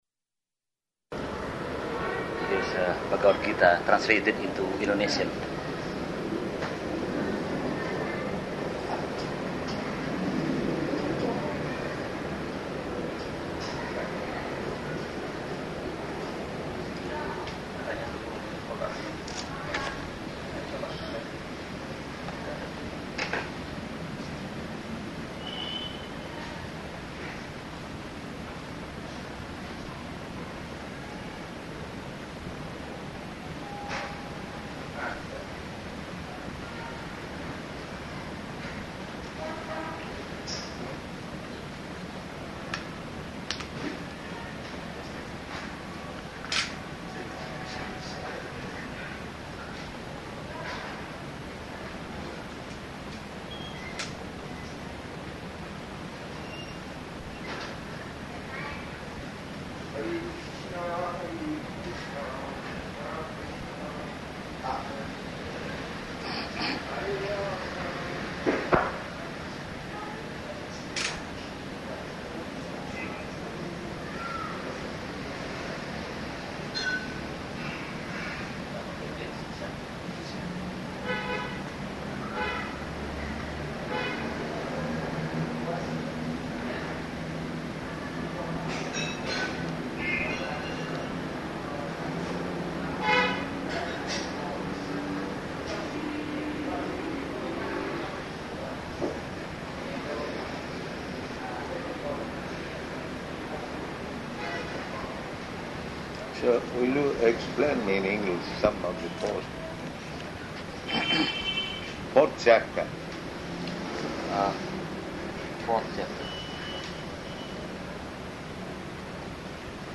Room Conversation with Indonesian Scholar
Room Conversation with Indonesian Scholar --:-- --:-- Type: Conversation Dated: February 27th 1973 Location: Jakarta Audio file: 730227R1.JKT.mp3 [poor audio] Scholar: This Bhagavad-gītā translated into Indonesian.